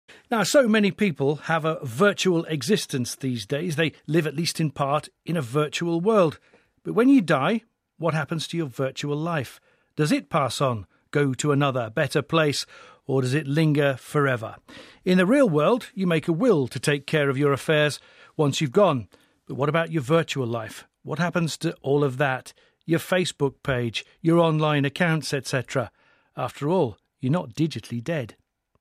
【英音模仿秀】虚实两世界 身死号不灭 听力文件下载—在线英语听力室